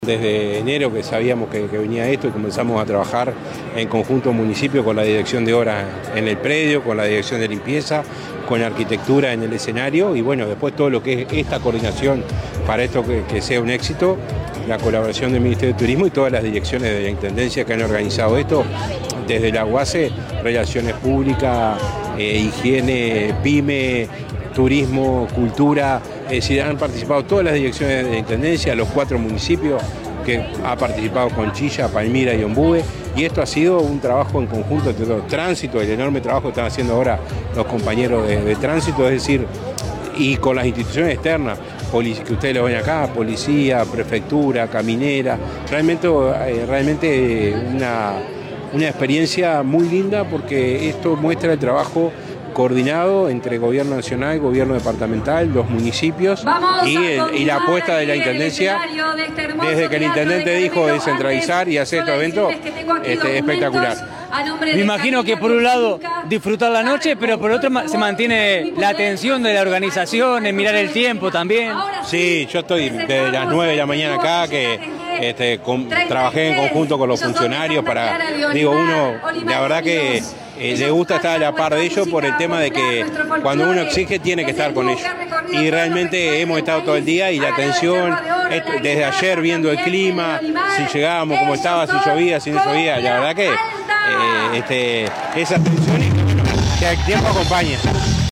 El intendente Guillermo Rodríguez y otras autoridades de la Intendencia acompañaron el evento y previo al espectáculo habló el Alcalde Pablo Parodi con los colegas de Radio Carmelo, celebrando el éxito del espectáculo.